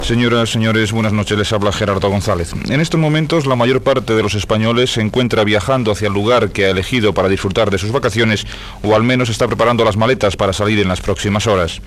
Consells de José Luis Martín , director de la Dirección General de Tráfico (DGT)
Informatiu